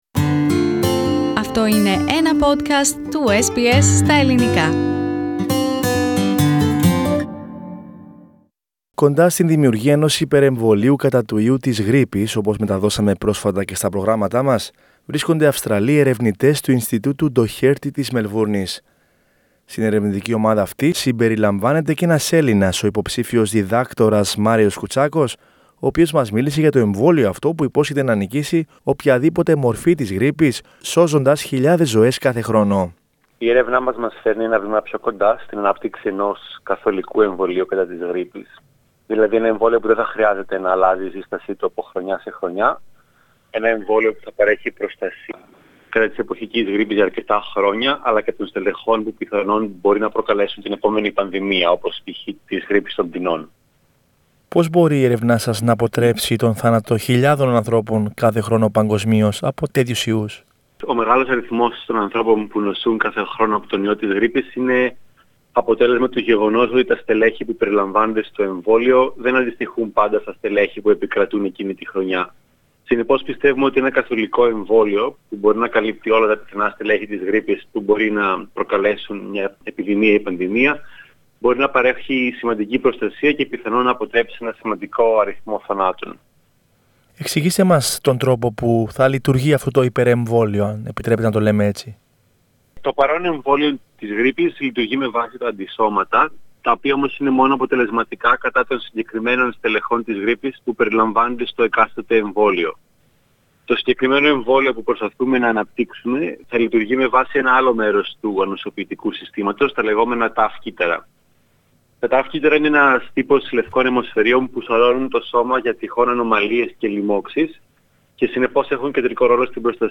μίλησε στο Ελληνικό Πρόγραμμα της Κρατικής Ραδιοφωνίας SBS